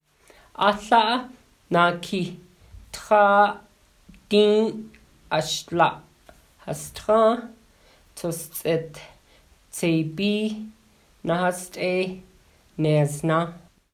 The first 10 numbers in the Navajo (Diné) language, recited
amplified slightly